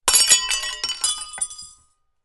На этой странице собраны звуки, связанные с зеркалами: от едва уловимых отражений до резких ударов.
Звук разбивающегося маленького зеркала